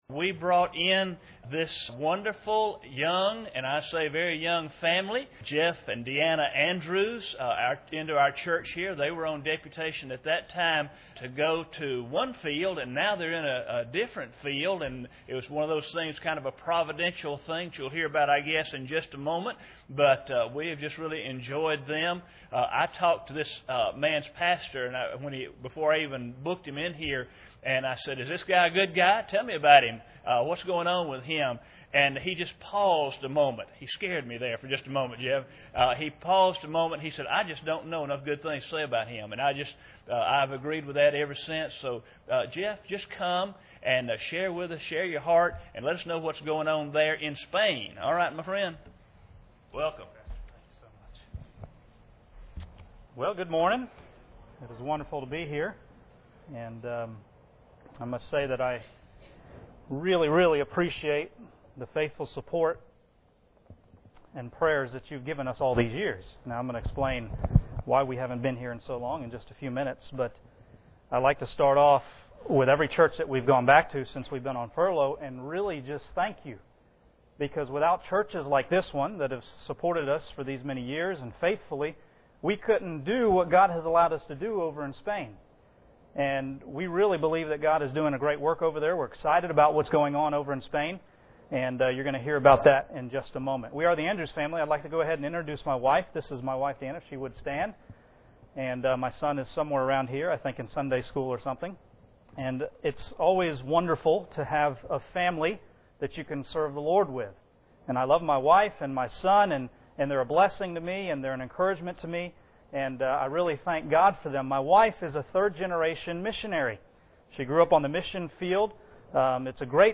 Missionary Report from Spain
Service Type: Sunday School Hour